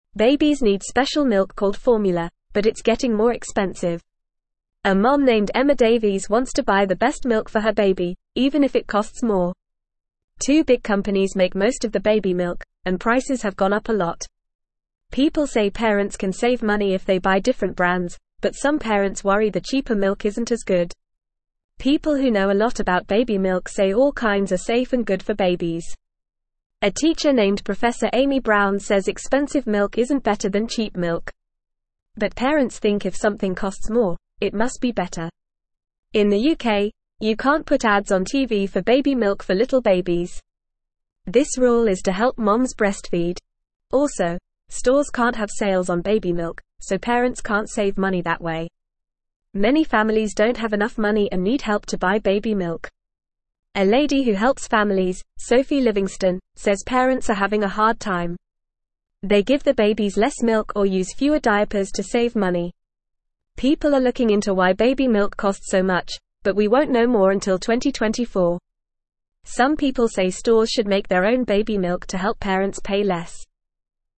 Fast
English-Newsroom-Lower-Intermediate-FAST-Reading-Expensive-Baby-Food-Cheaper-Options-Are-Good.mp3